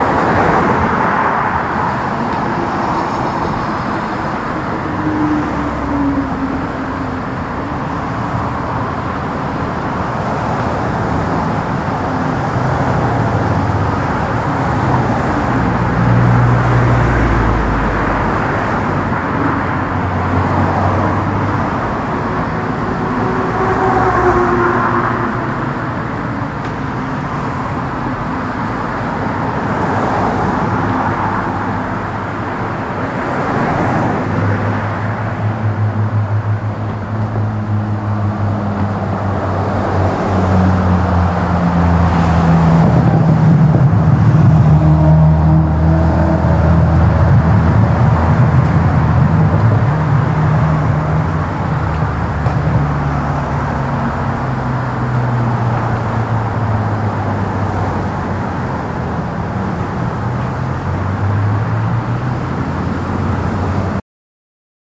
carAmbience.mp3